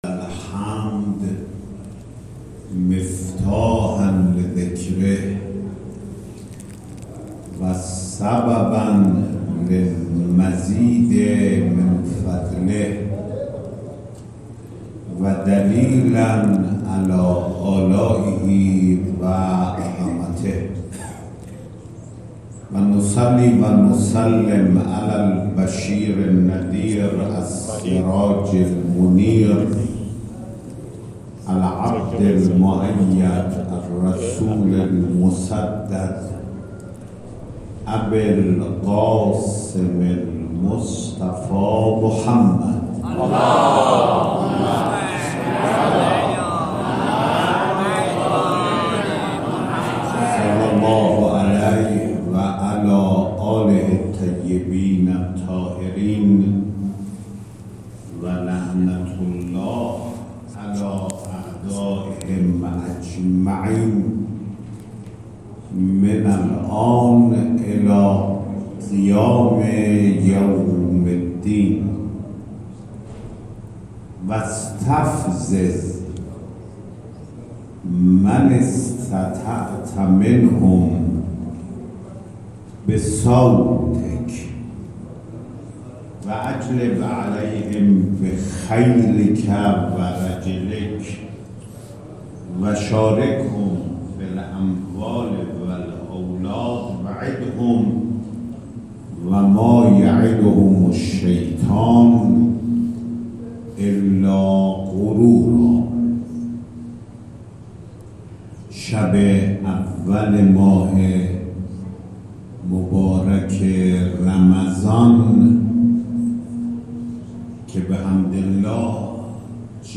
🔹این مراسم با سخنرانی آیت‌الله سیداحمدخاتمی امام جمعه موقت تهران و عضو مجلس خبرگان رهبری و با حضور نماینده ولی فقیه و امام جمعه کاشان، بیت محترم مرحوم آیت الله امامی کاشانی(ره)، فرماندار، روساء ادارات ، مدارس علمیه،جامعه روحانیت و دیگر اقشار مردم در مسجد میرعماد کاشان برگزار شد.